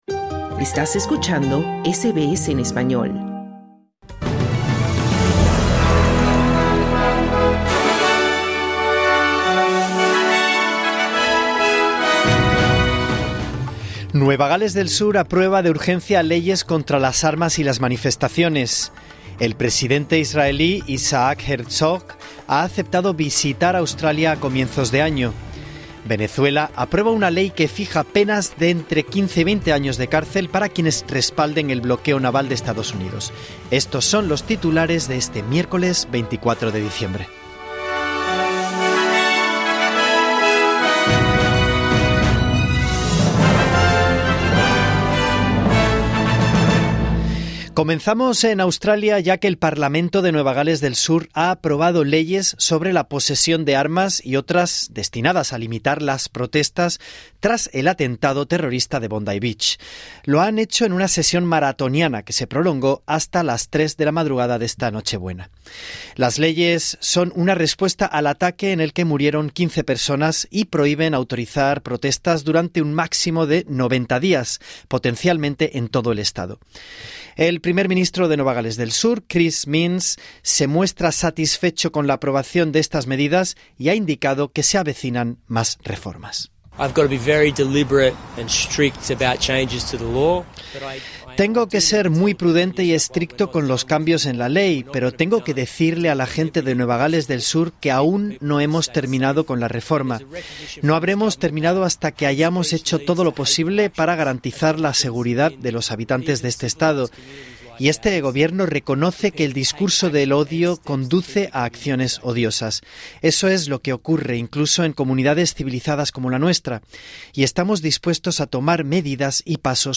Boletín de noticias del 24 de diciembre: el Parlamento de Nueva Gales del Sur aprueba de urgencia leyes contra armas y protestas tras el atentado de Bondi Beach, el presidente israelí, Isaac Herzog, visitará Australia a comienzos de 2026, Venezuela aprueba una ley que fija penas de 20 años de cárcel para quienes respalden bloqueo naval de Estados Unidos.